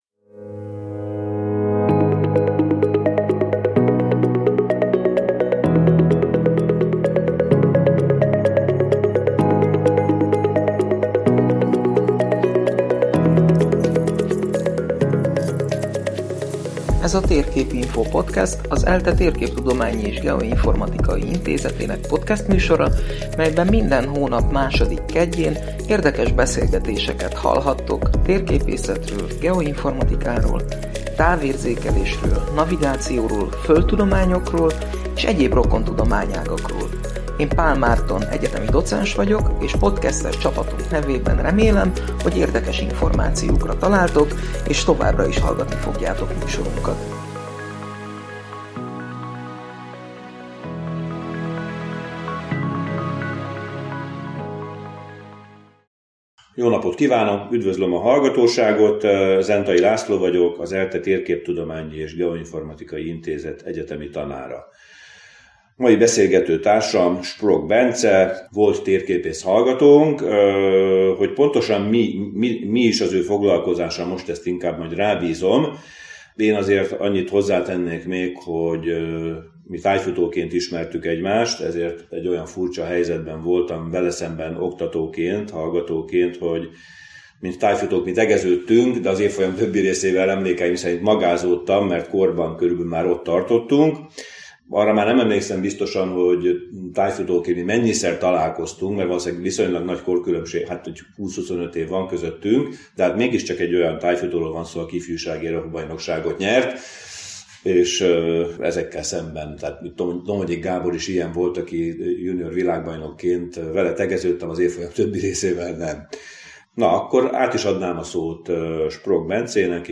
A beszélgetésből kiderül: 🧫 mi történik, ha egy sejtben káros anyagok halmozódnak fel, 👵 hogyan befolyásolja az öregedés a sejtek működését, 🦟 hogyan lehet muslicákkal dolgozni laboratóriumban, 🎓 és mit ad…